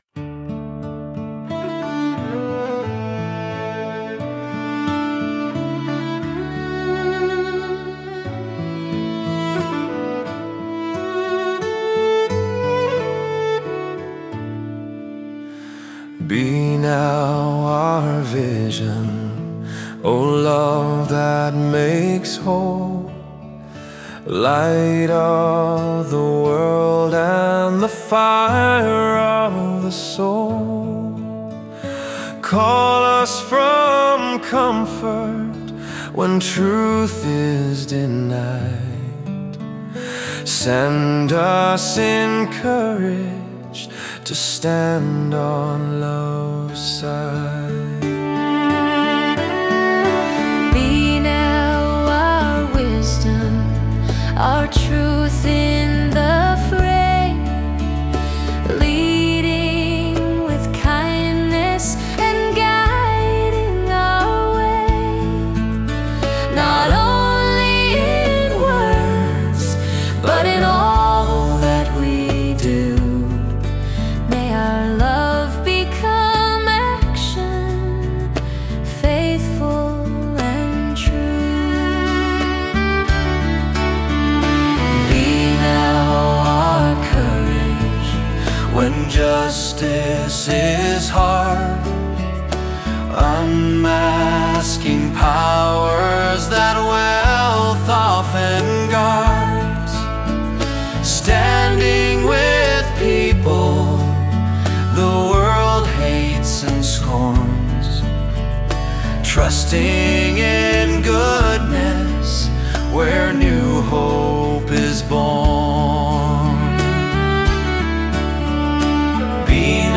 I recently wrote new lyrics to the old hymn melody “Be Thou My Vision.” It is based on a traditional Irish tune called “Slane.”
This is a recent recording which is fairly true to the melody of the hymn. (I think I have finally got the hang of the Suno AI software that I used to produce it.)